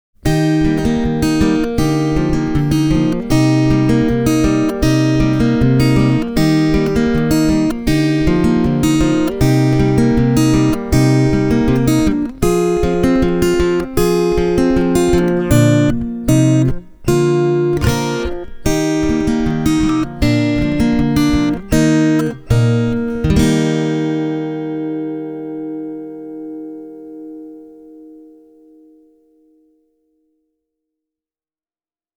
The all-maple body gives you a lively tone with a tight, sinewy bottom end, a clear mid-range (typical of maple-bodied steel-strings), as well as a nicely rounded top end.
Fishman have managed to filter out most of the infamous nasal quack and attack click, so often found on lesser piezo systems.
I recorded the following examples both acoustically (using an AKG C3000) and direct (with the Fishman’s EQ flat):
Fingerstyle –  PreSys+
fingerstyle-e28093-fishman-presys.mp3